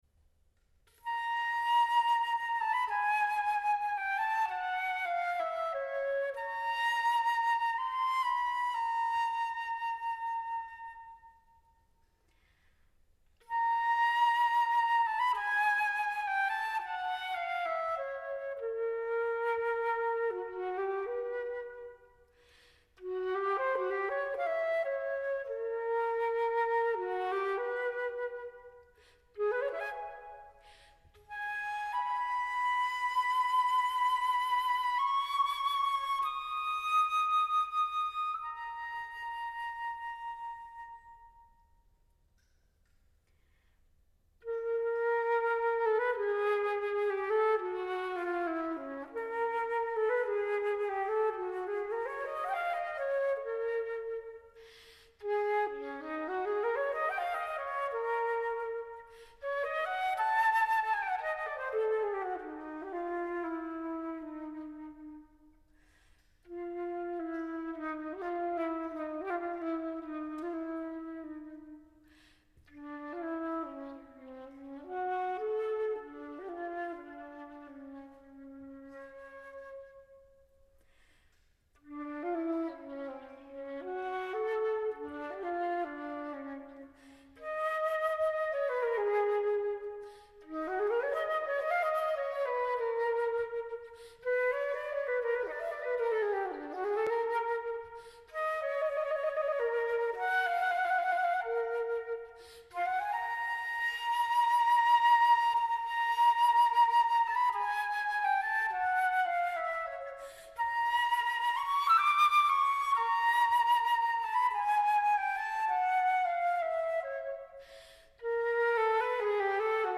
Giornata nazionale della musica svizzera
Auditorio Stelio Molo RSI, Lugano